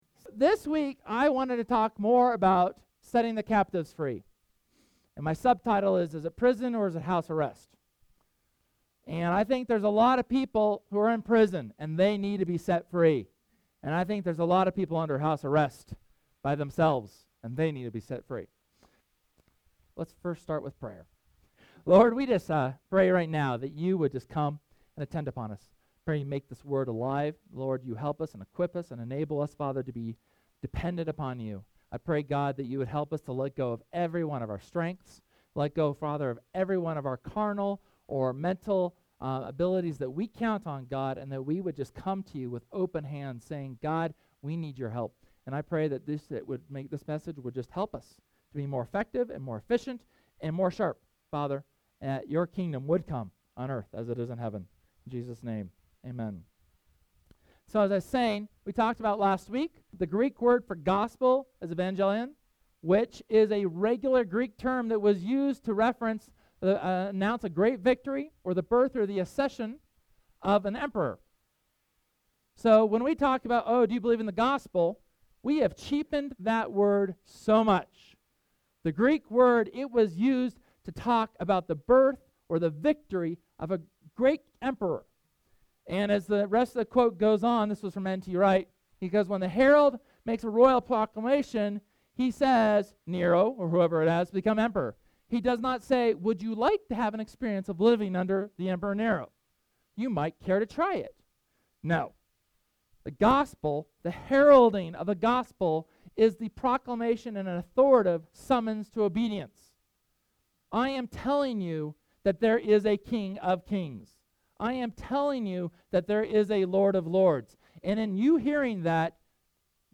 Sermon from Sunday, January 27th discussing the importance and process of resisting and casting out evil spirits.